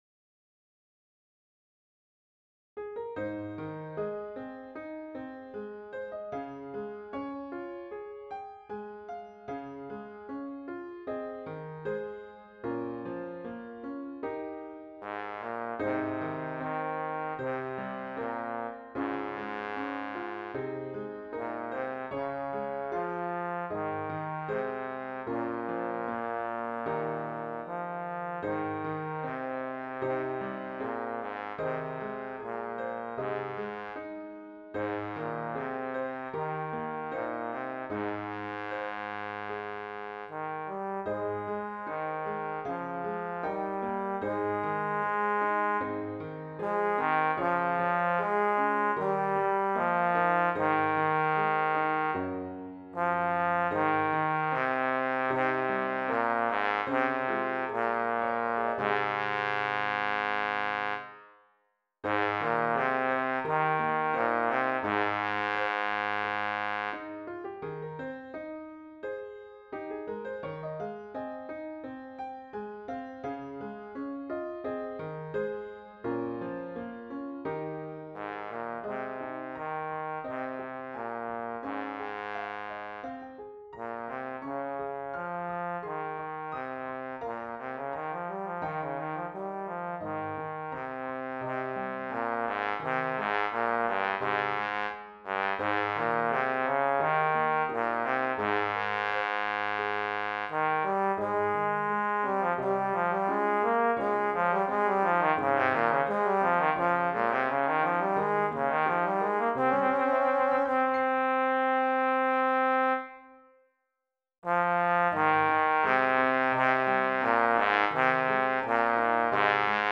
Intermediate Instrumental Solo with Piano Accompaniment.
Christian, Gospel, Sacred, Folk.
A Hymn arrangement
put to a flowing folk setting.